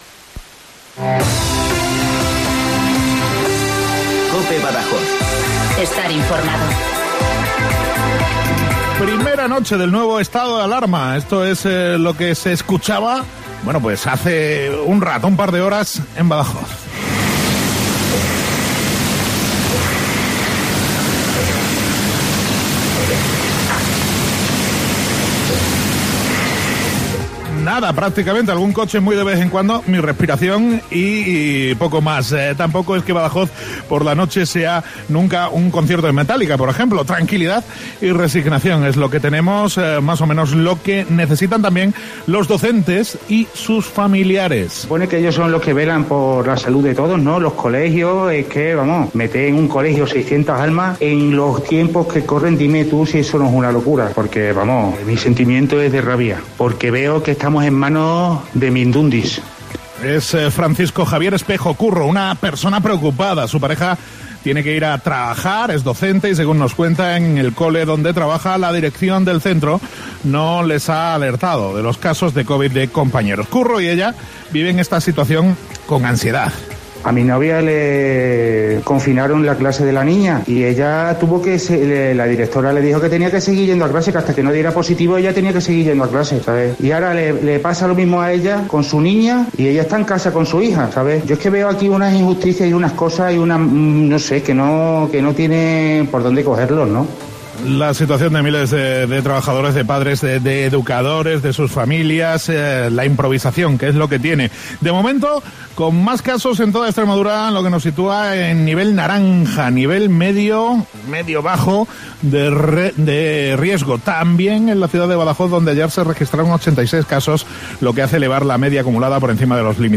NOTICIAS 08:24 - Lunes, 26 de Octubre de 2020